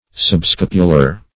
subscapular - definition of subscapular - synonyms, pronunciation, spelling from Free Dictionary
Search Result for " subscapular" : The Collaborative International Dictionary of English v.0.48: Subscapular \Sub*scap"u*lar\, Subscapulary \Sub*scap"u*la*ry\, a. (Anat.)